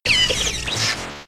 Cri de Raichu K.O. dans Pokémon X et Y.